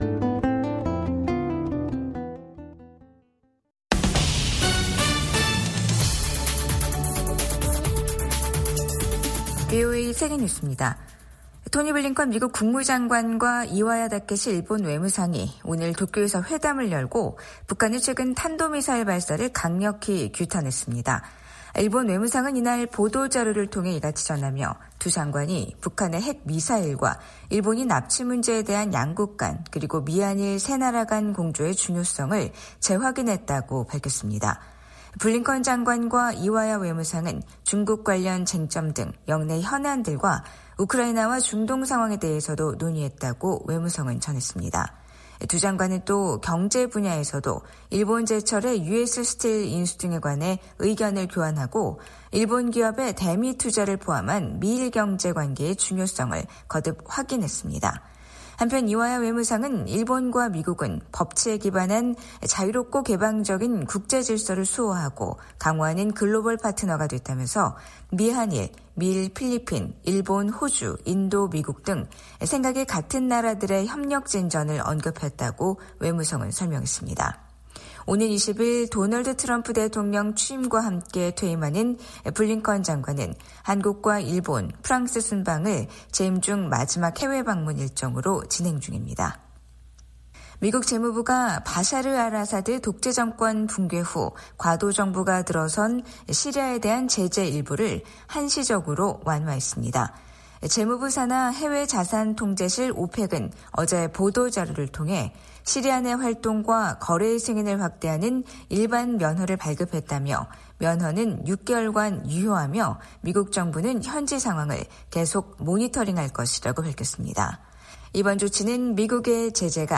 VOA 한국어 간판 뉴스 프로그램 '뉴스 투데이', 2025년 1월 7일 3부 방송입니다. 북한은 어제(6일) 신형 극초음속 중장거리 탄도미사일 시험발사에 성공했다며 누구도 대응할 수 없는 무기체계라고 주장했습니다. 미국과 한국의 외교장관이 북한의 탄도미사일 발사를 강력히 규탄했습니다.